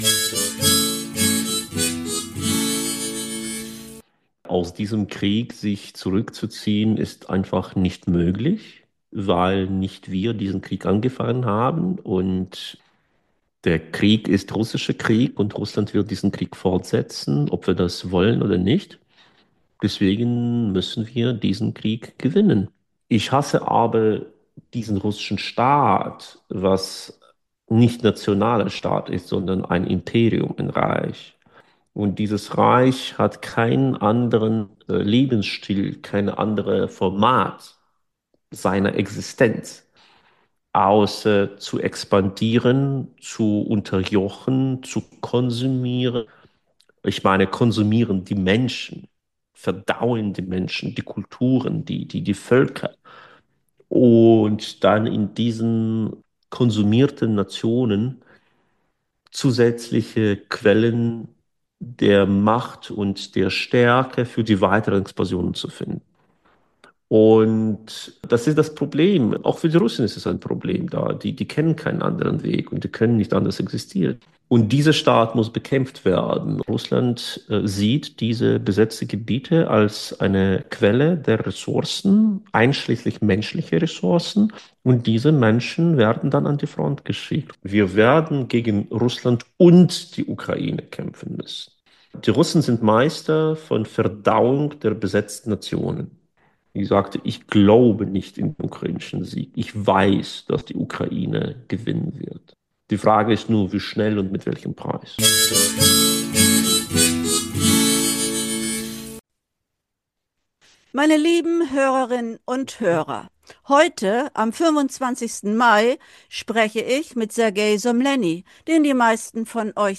Bürger tun was dazu: Interview mit dem Bürger